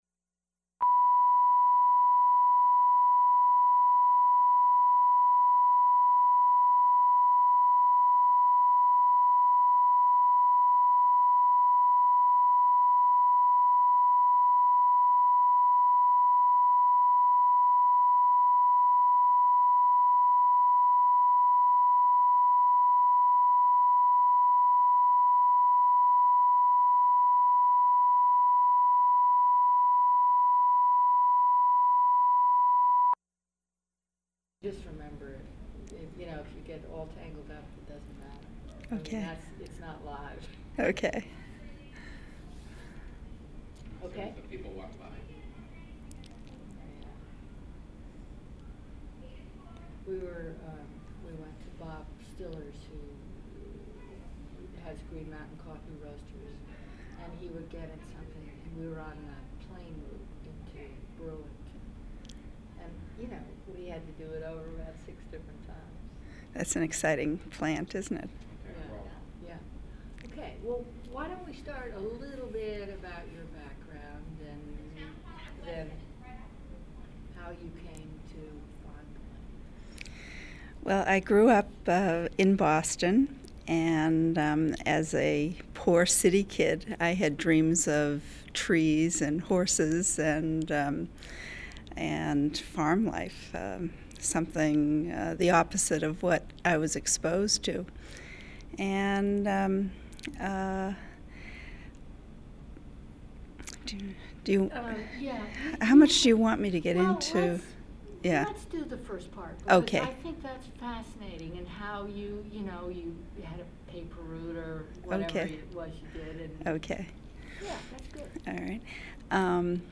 Interview
sound cassette (analog)